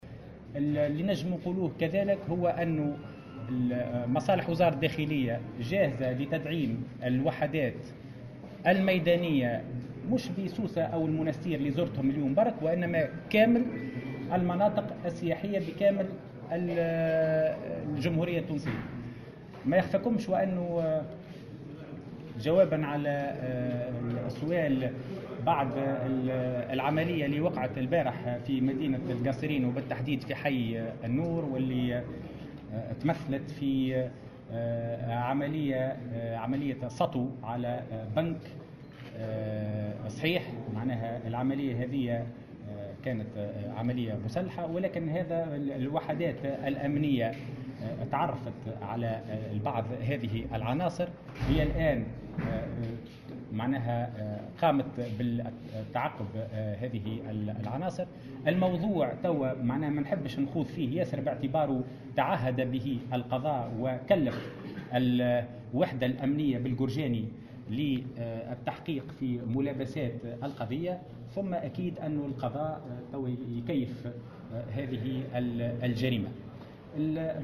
أكد وزير الداخلية هشام الفوراتي في تصريح لمراسل الجوهرة "اف ام" على هامش زيارته إلى ولاية المنستير مساء اليوم أنه تم التعرف على بعض المتورطين في جريمة السطو التي استهدفت فرعا بنكيا بالقصرين أمس.